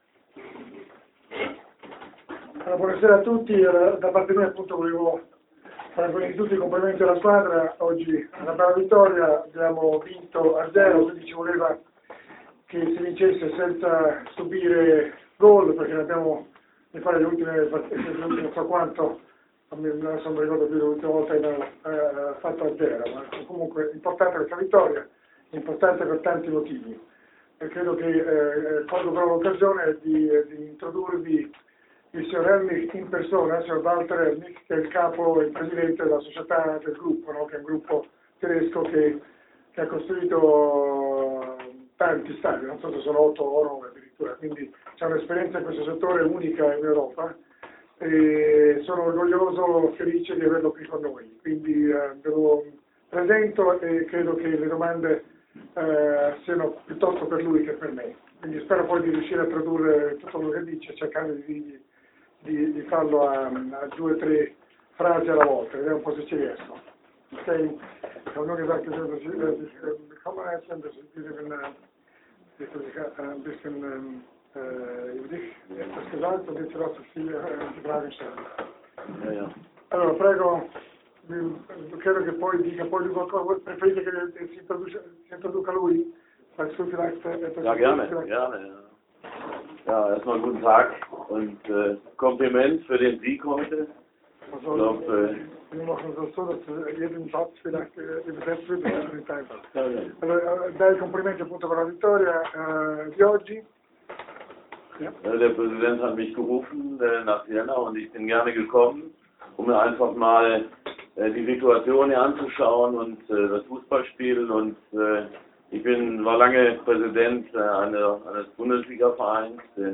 Robur Siena- Foligno Calcio 2-0: conferenza stampa